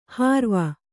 ♪ hārva